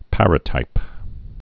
(părə-tīp)